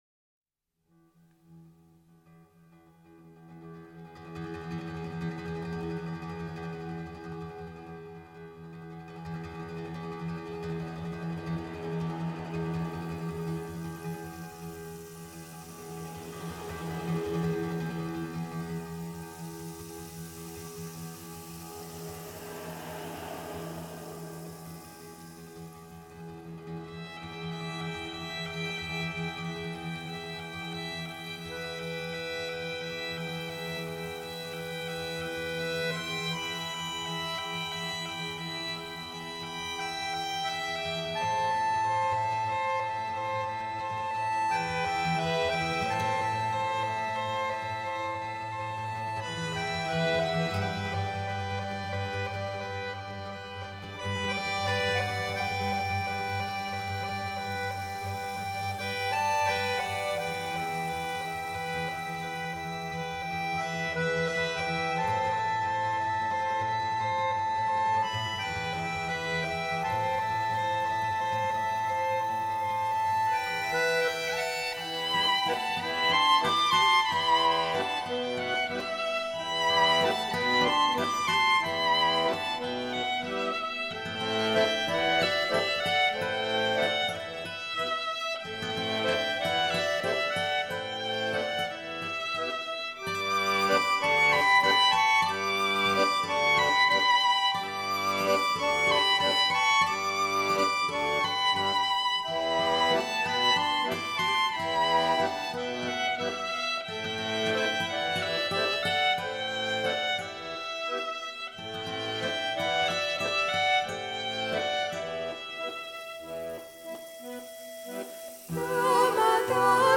violon, voix
alto, voix, guitare
accordéon, voix, darbuka
chant grec